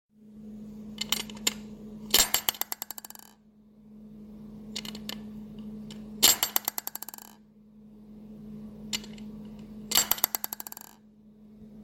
恐怖楼梯2
描述：用接触式麦克风对着楼梯栏杆录音。
标签： 拨浪鼓 楼梯 悬念
声道立体声